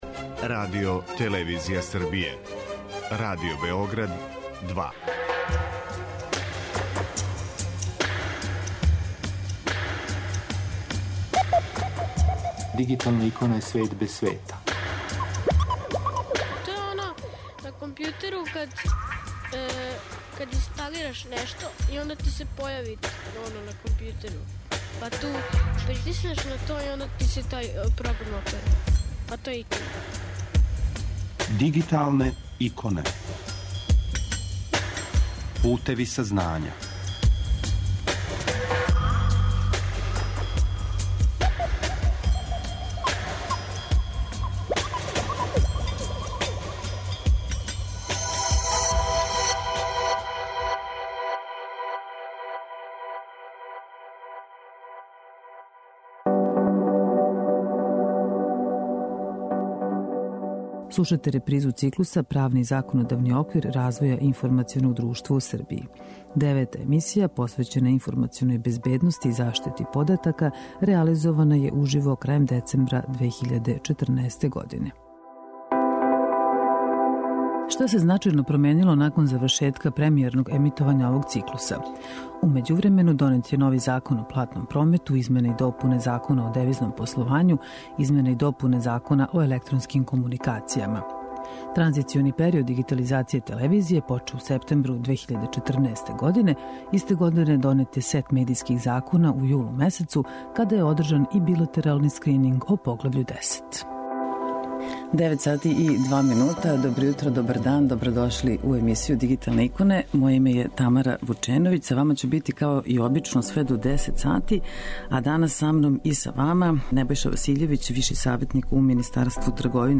Девета емисија, посвећена информационој безбедности, приватности и заштити података, реализована је уживо крајем децембра 2014. године.